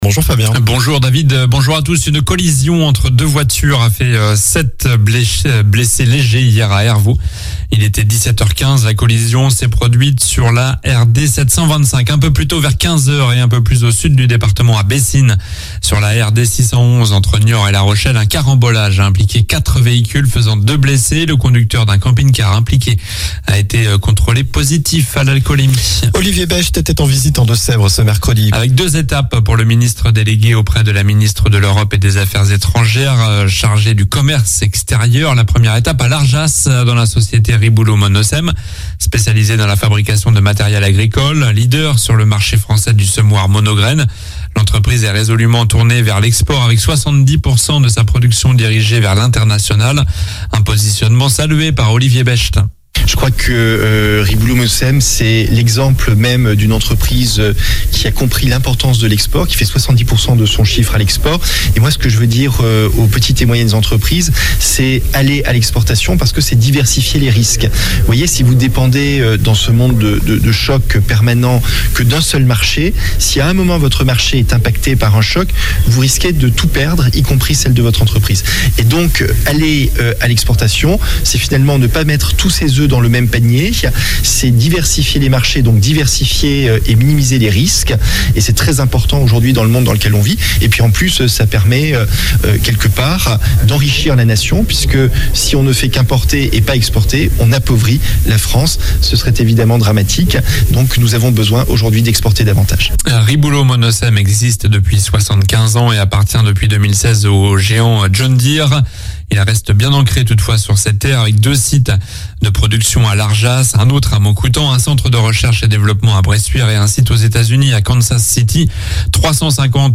Journal du jeudi 27 juillet (matin)